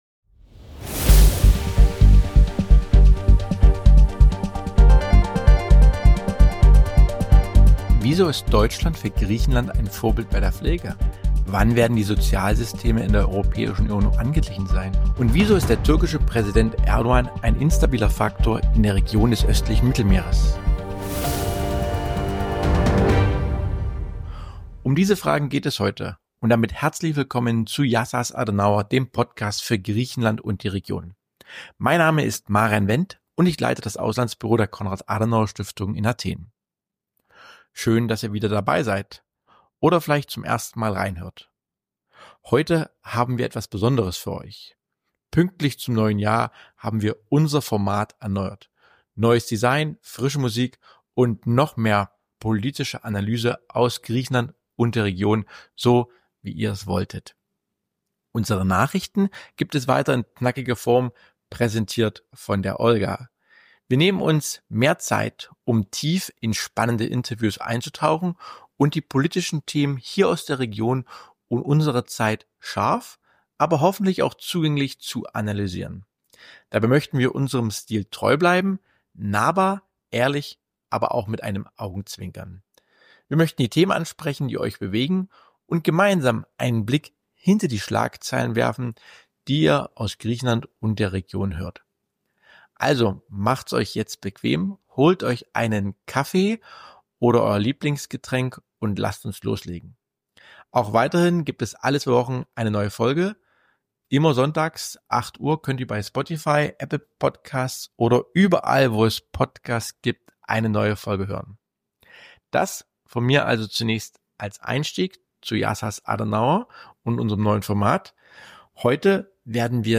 Host Marian Wendt hat auch gleich einen prominenten Gesprächsgast: Den stellvertretenden griechischen Gesundheitsminister Dimitris Vartzopoulos.